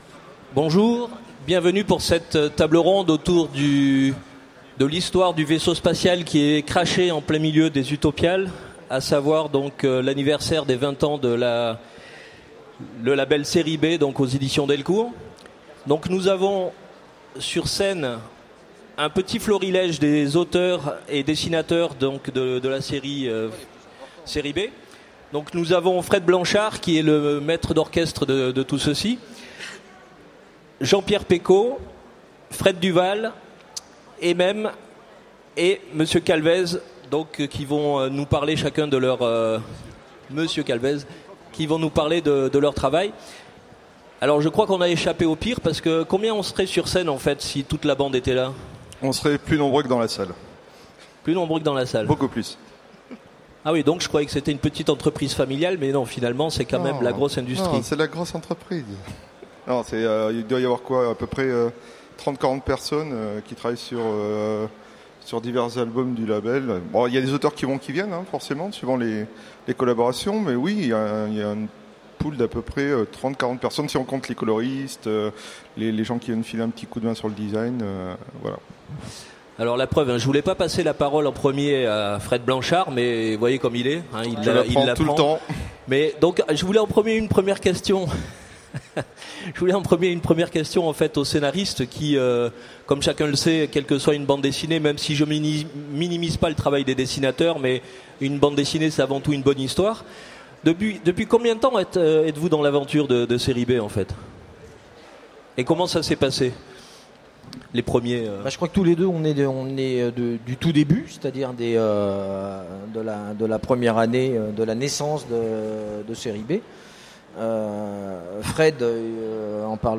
Utopiales 2015 : Conférence Les 20 ans de Série B !